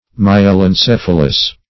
Search Result for " myelencephalous" : The Collaborative International Dictionary of English v.0.48: Myelencephalous \My`e*len*ceph"a*lous\, a. (Zool.)